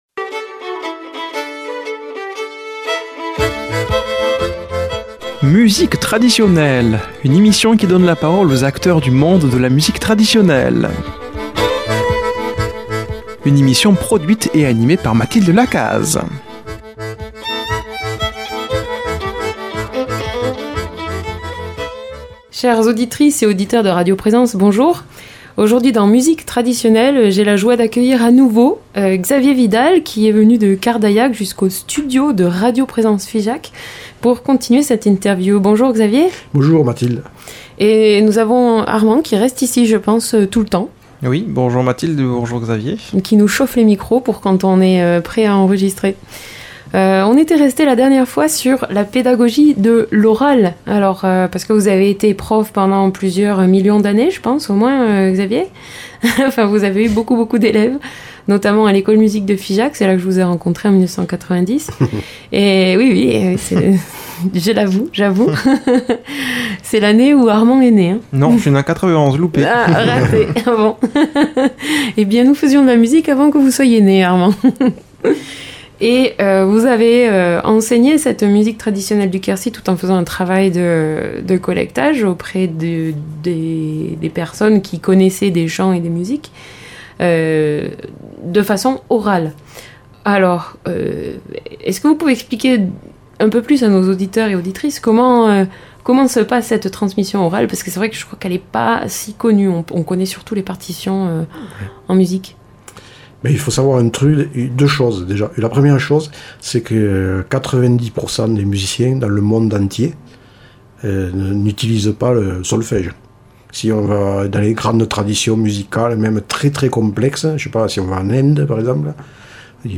Nouvelle série d'émissions Musique Traditionelle